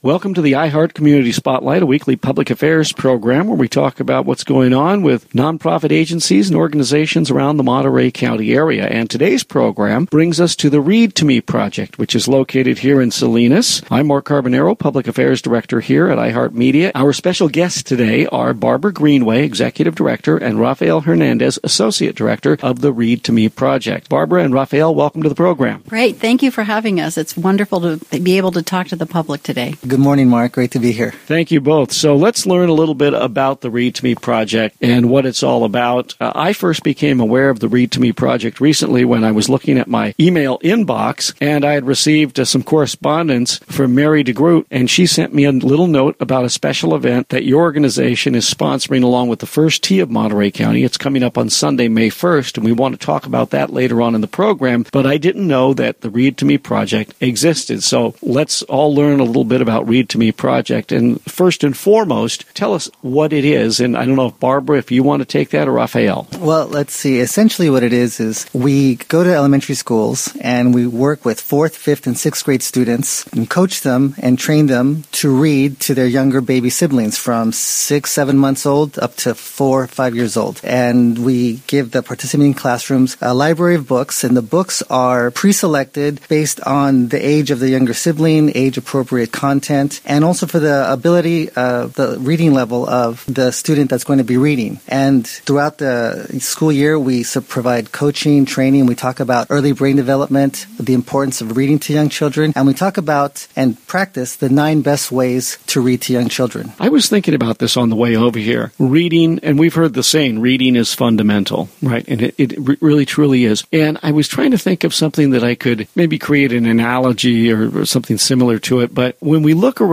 iHeart Radio – Interview